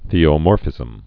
(thēō-môrfĭzəm)